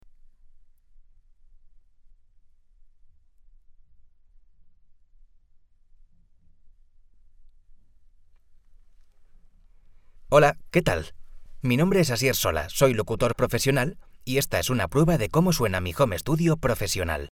Male
TEENS, 20s, 30s
Bright, Natural, Smooth, Soft, Versatile, Young
Microphone: Neumann TLM-103
Audio equipment: Professional Soundproof Vocal Booth, SSL2+ interface